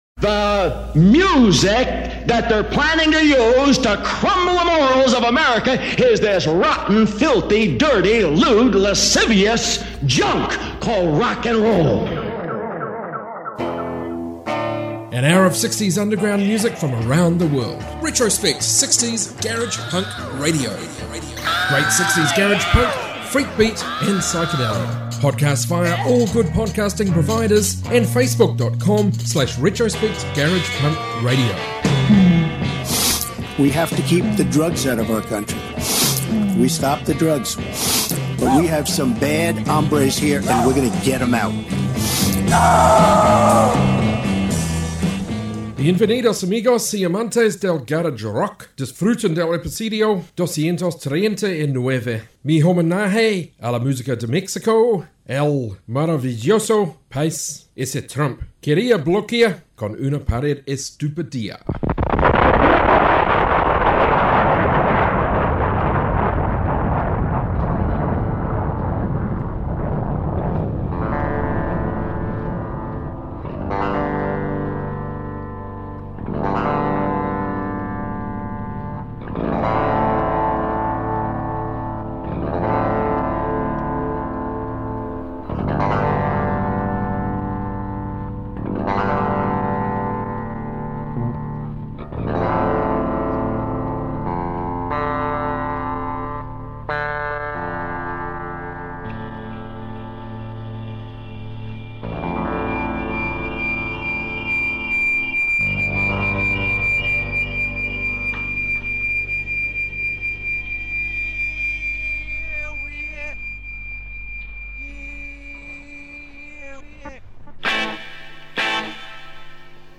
60s Garage Punk, Garage Rock, Proto-punk, Freakbeat, Psychedelia